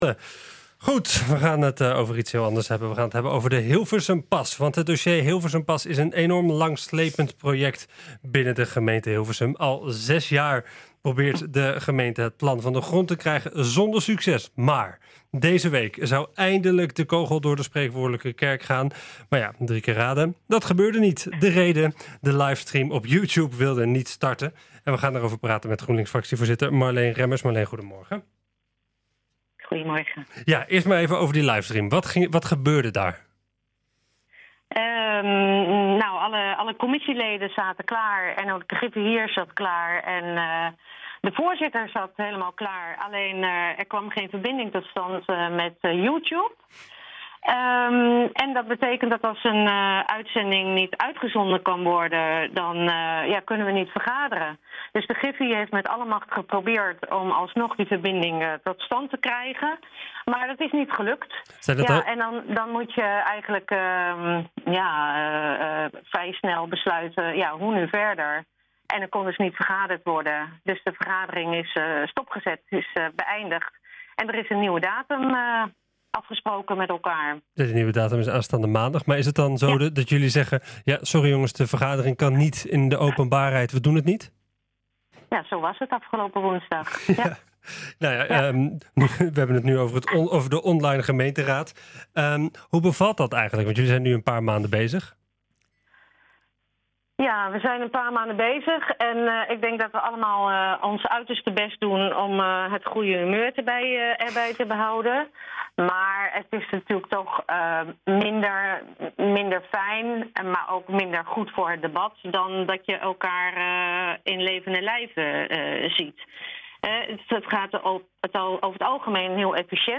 De livestream op YouTube wilde niet starten. We gaan erover praten met GroenLinks Fractievoorzitter Marleen Remmers.